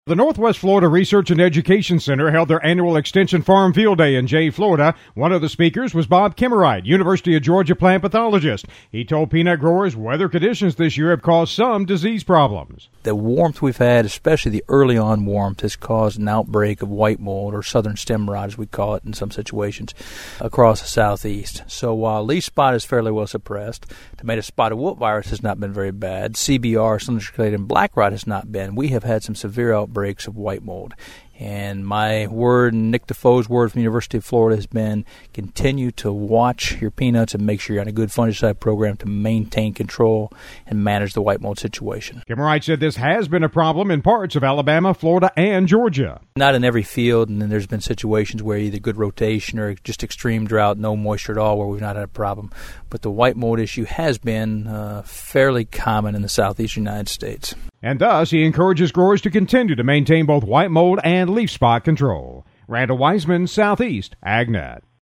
The Northwest Florida Research and Education Center held their annual Extension Farm Field Day in Jay, Florida Thursday.